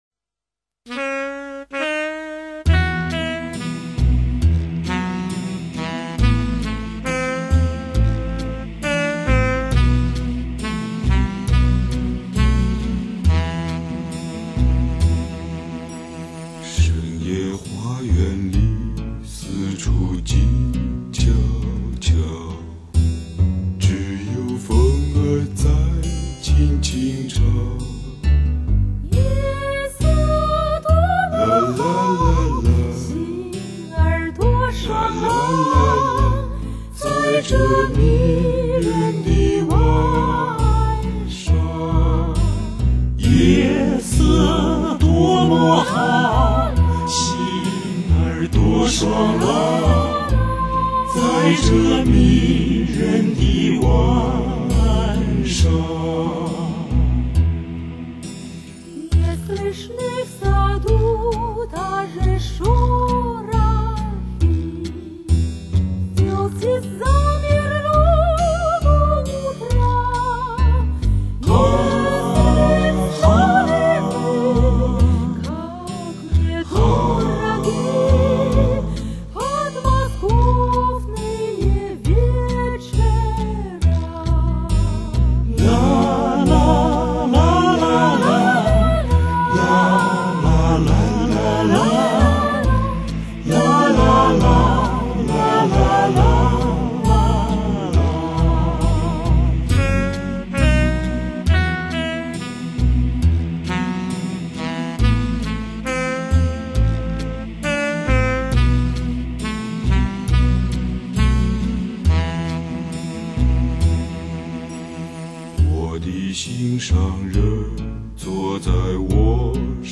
青年男女歌唱家、重唱珠联璧合、唯美专业典范、中国民歌与苏联民歌、交相辉映、璀璨多姿迷情。